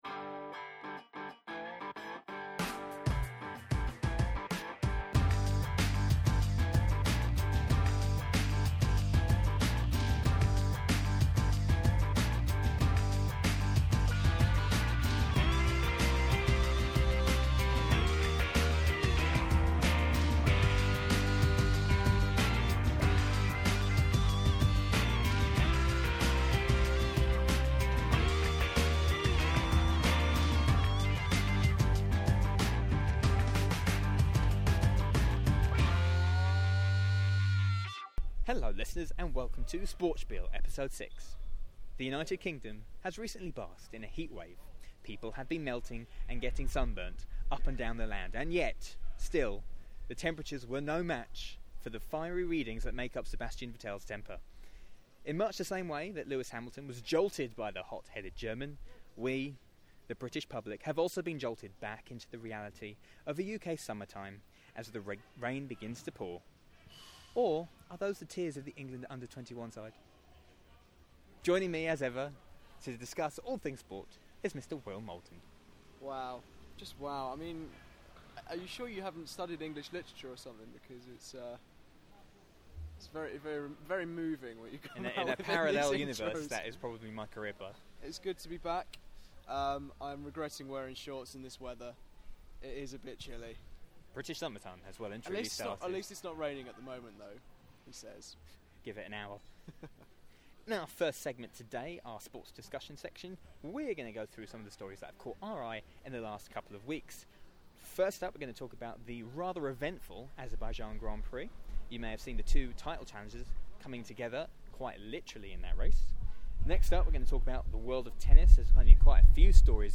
Our feature interview segment in this episode is with Paralympic wheelchair racing star Sammi Kinghorn.